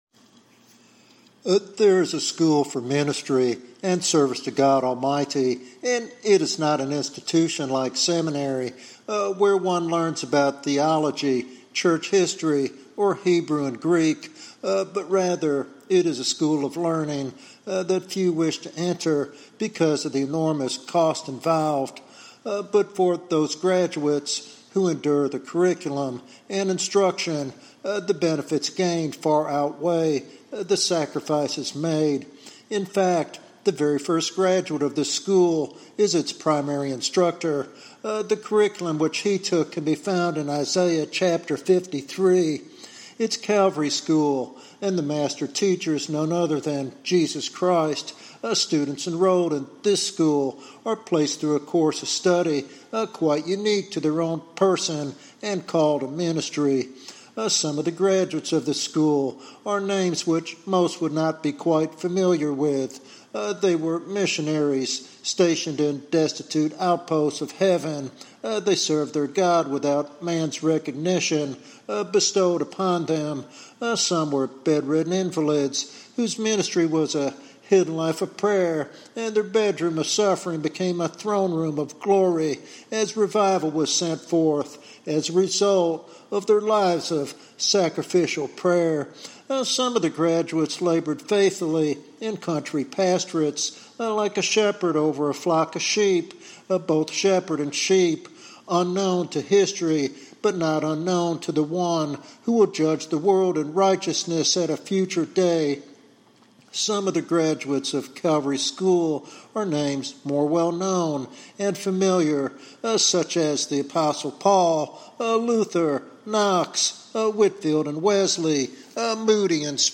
This sermon challenges listeners to embrace the crucified life for a ministry empowered by God’s glory.